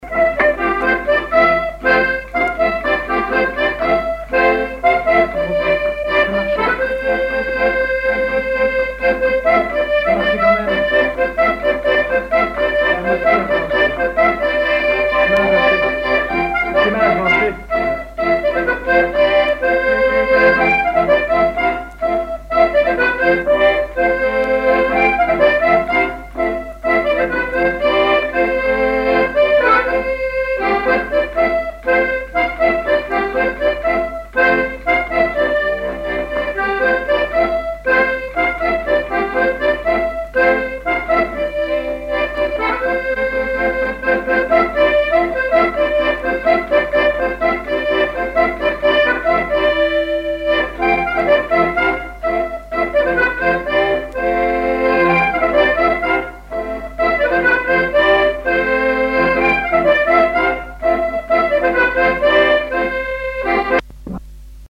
Mémoires et Patrimoines vivants - RaddO est une base de données d'archives iconographiques et sonores.
danse : ronde : grand'danse
accordéon diatonique
Pièce musicale inédite